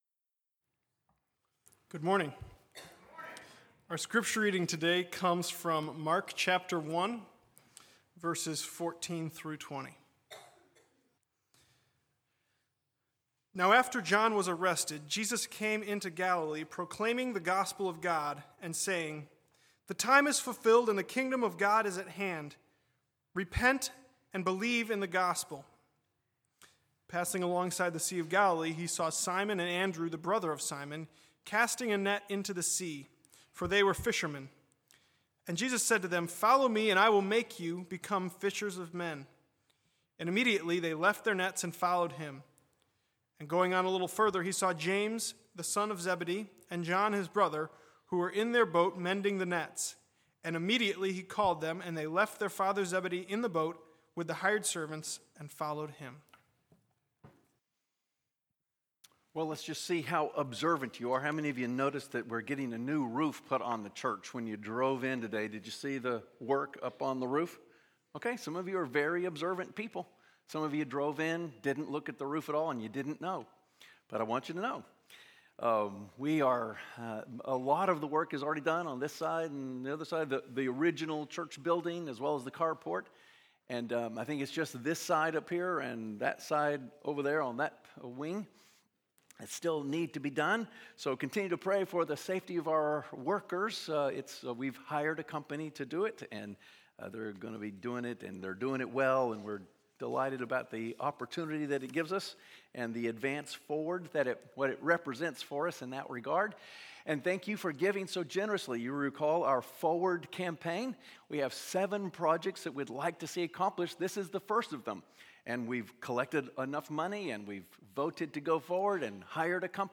Bible Text: Mark 1:14-20 | Preacher